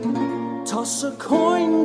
toss-a-coin-1.mp3